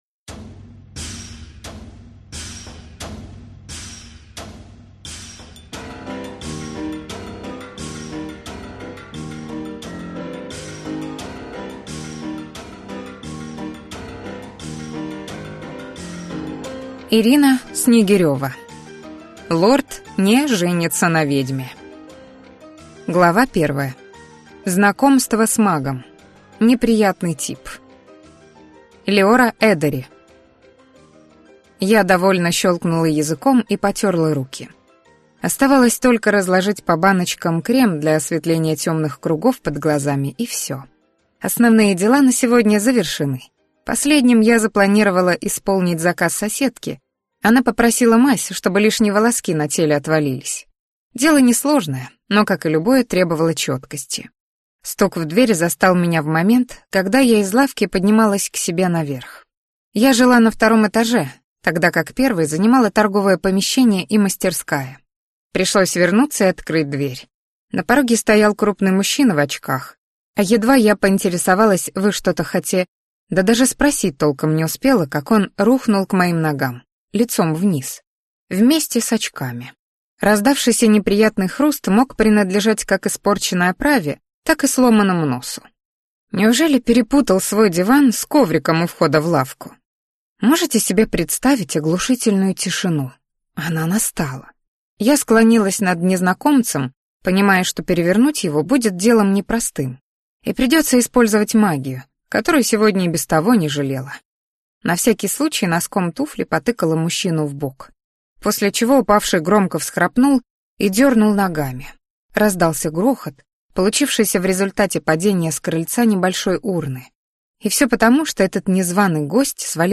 Аудиокнига Лорд (не) женится на ведьме | Библиотека аудиокниг